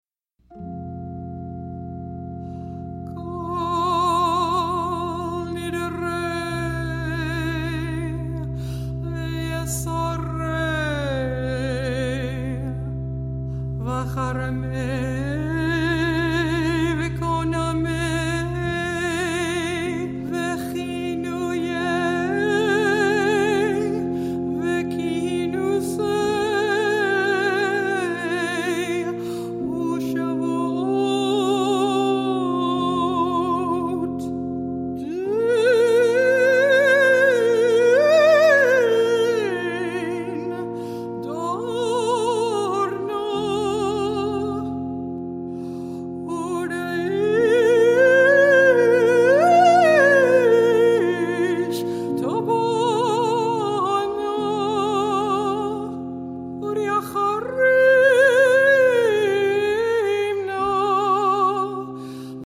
mezzo-soprano
organ
piano